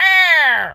bird_vulture_hurt_03.wav